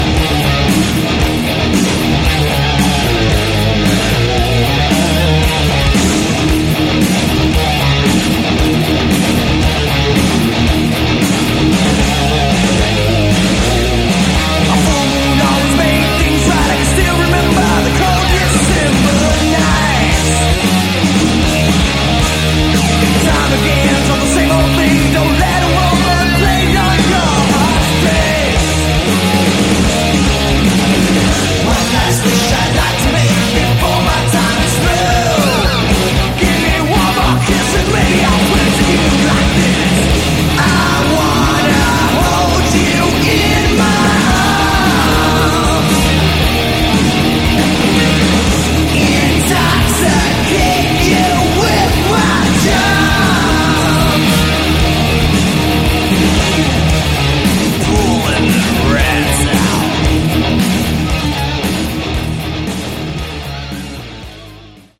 Category: Hard Rock